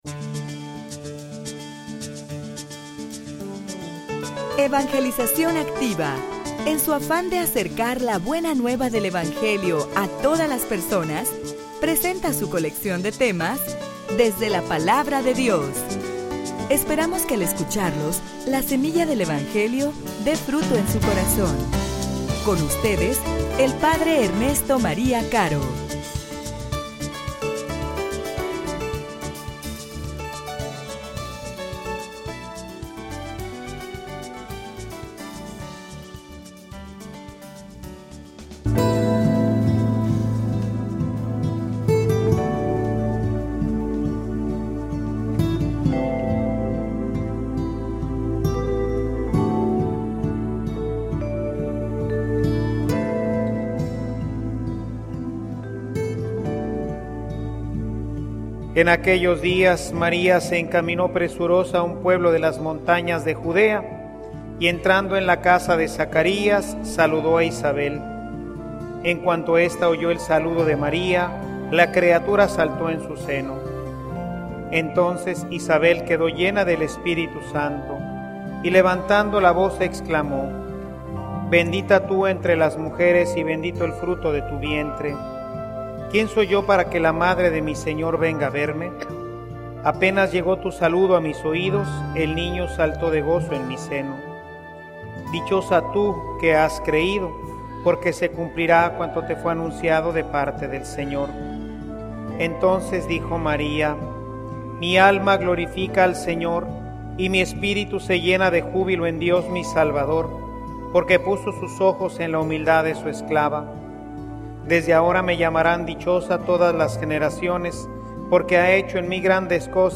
homilia_El_gran_Signo.mp3